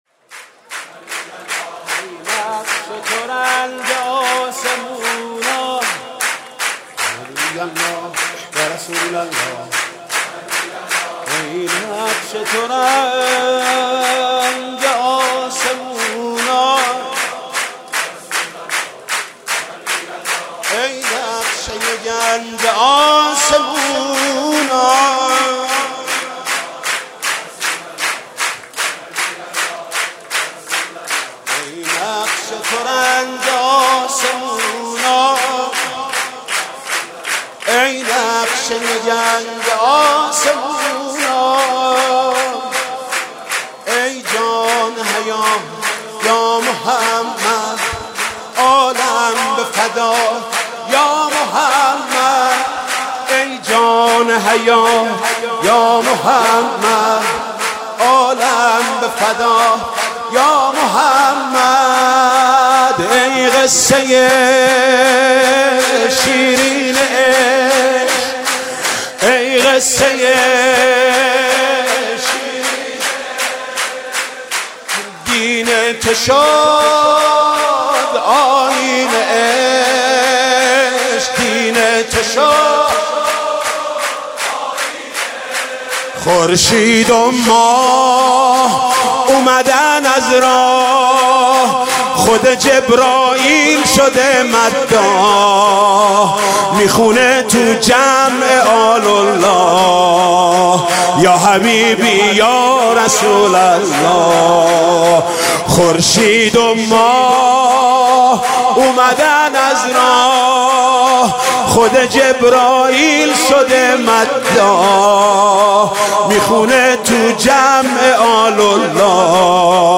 مولودی خوانی ولادت نبی اکرم (ص) و امام صادق(ع) / محمود کریمی
برچسب ها: مولودی ، پیامبر اسلام ، امام صادق ، محمود کریمی